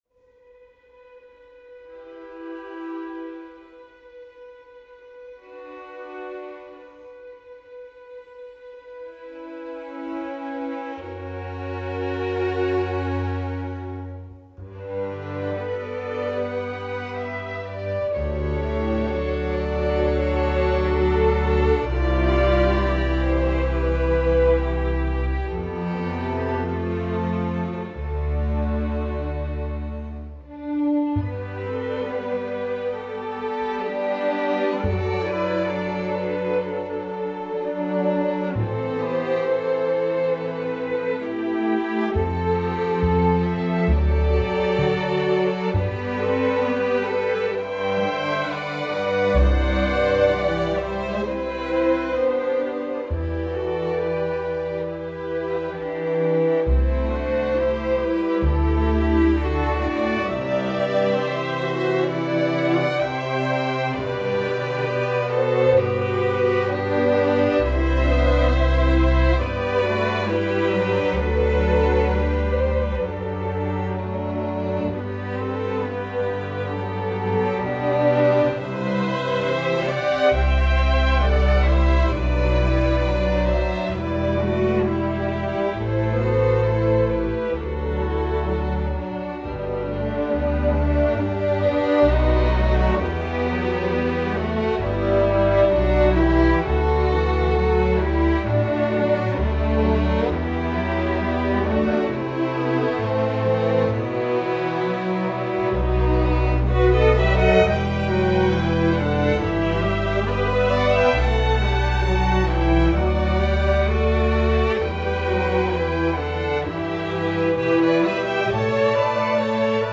Category: String Orchestra